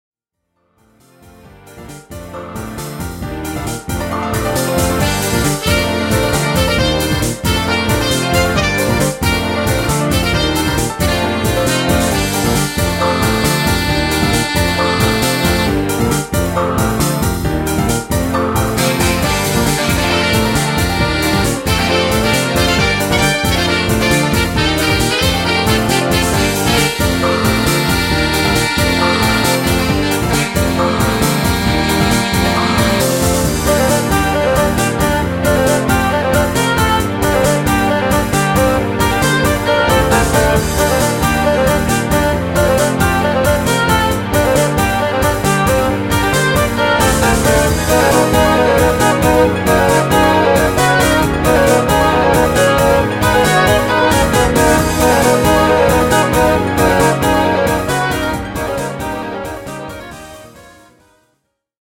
東方風自作曲
できるだけ道中っぽく作ったつもりです。